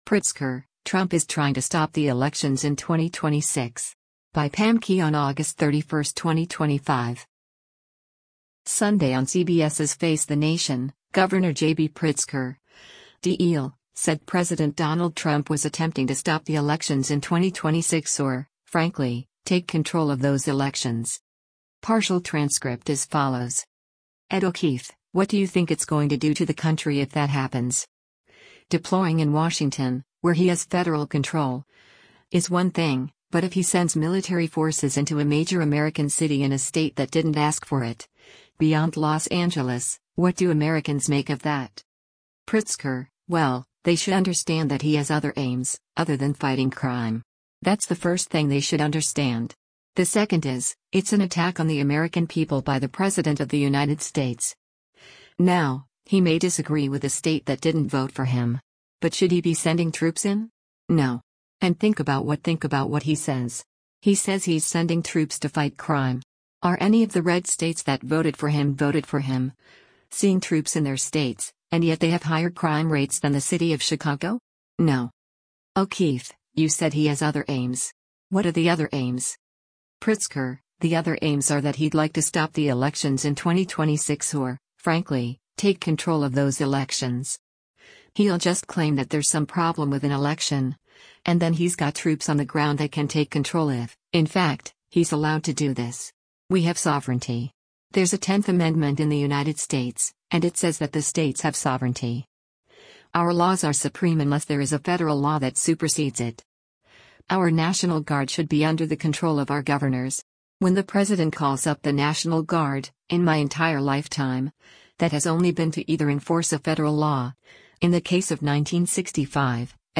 Sunday on CBS’s “Face the Nation,” Gov. J.B. Pritzker (D-IL) said President Donald Trump was attempting to “stop the elections in 2026 or, frankly, take control of those elections.”